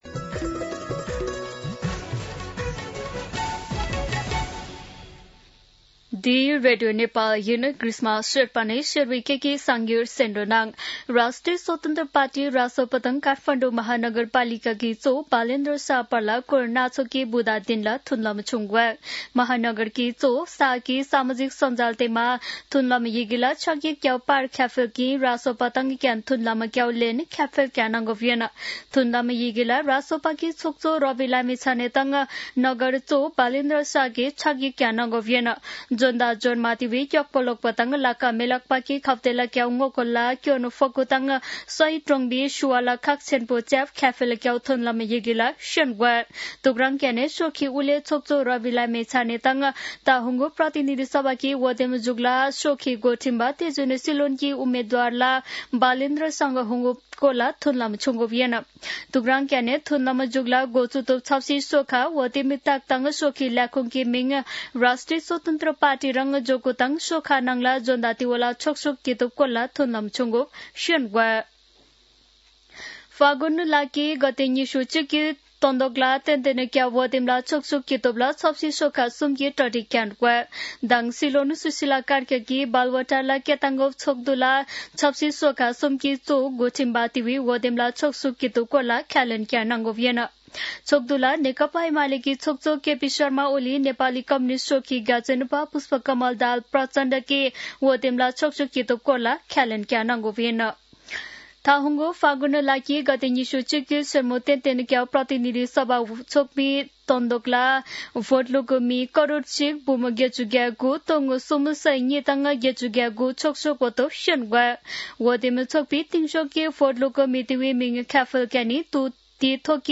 शेर्पा भाषाको समाचार : १३ पुष , २०८२
Sherpa-News-13.mp3